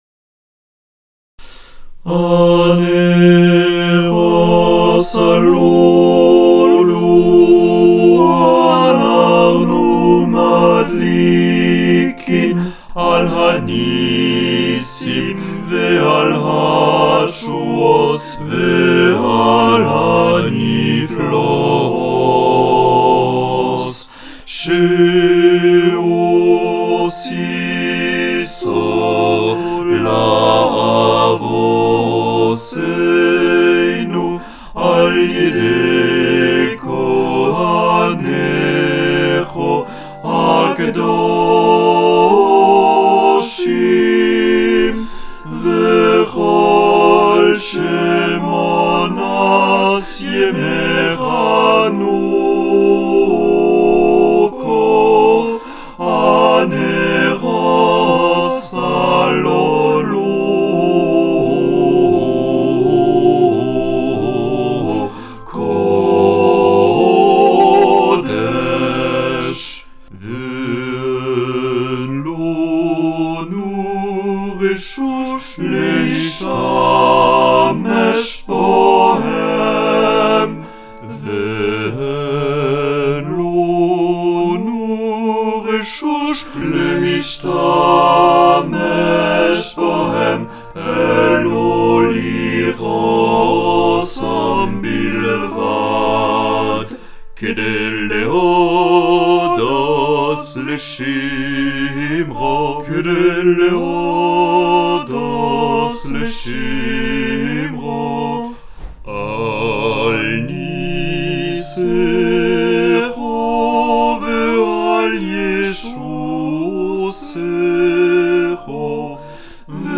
Number of voices: 4vv Voicing: SATB Genre: Sacred, Unknown
Language: Hebrew Instruments: A cappella